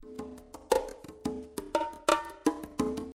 Результаты для Sound Forge (пресет Music 1)
Congas:
При сжатии пропадают удары, при растяжении - дублируются. Плавает темп.
Congas_SF_130.mp3